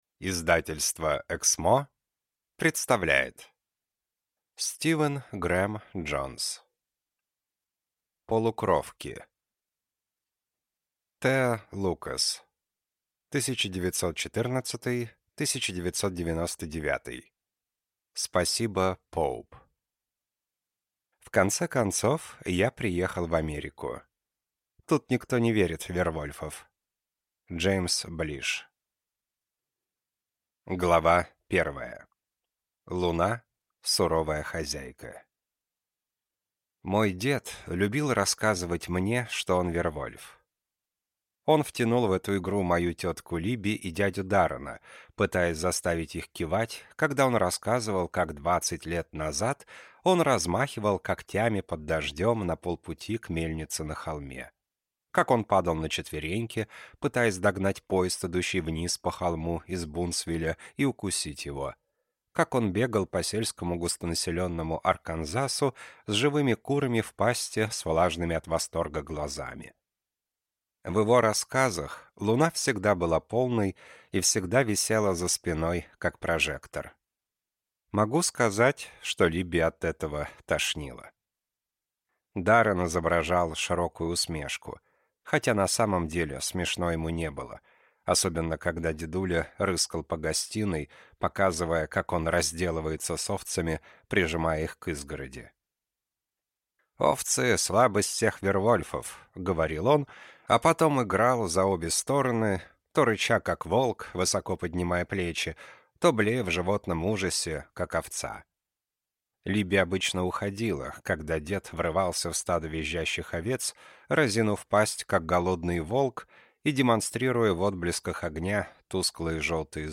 Аудиокнига Полукровки | Библиотека аудиокниг